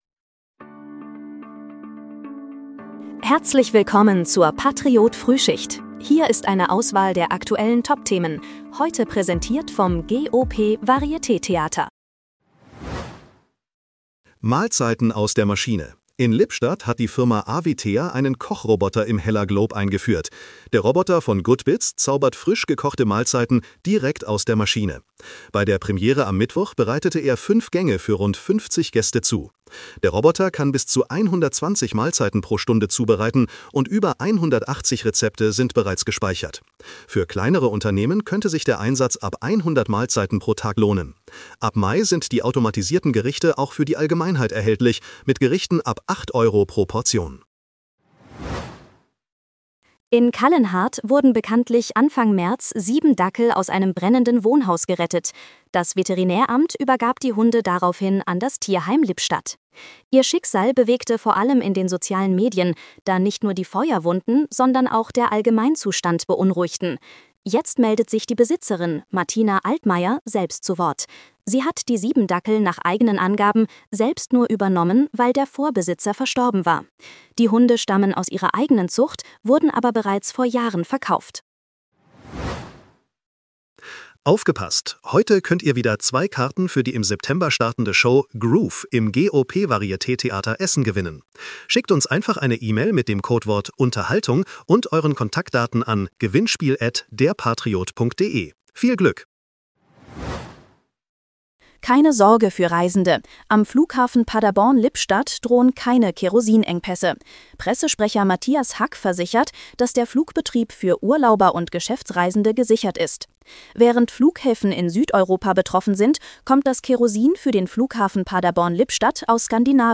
Dein morgendliches News-Update